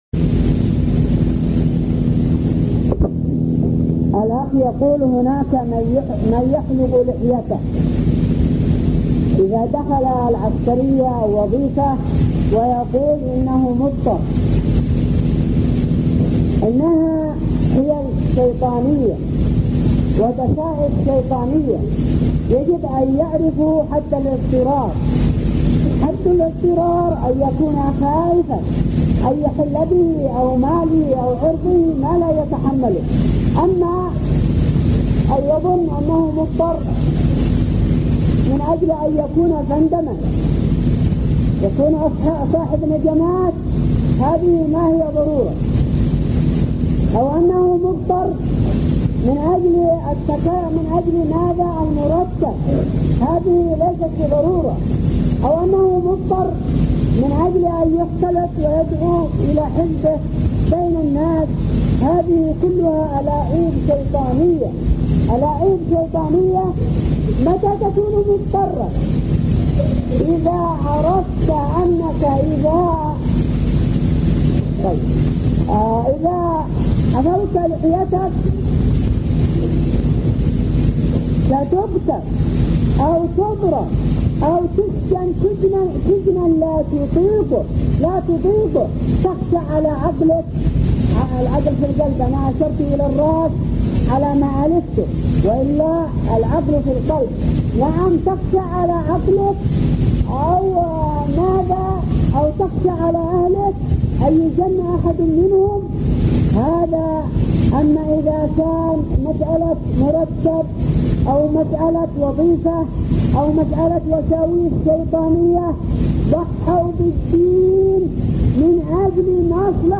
DhH 9 1436 forumsalafy Kategori: Fatwa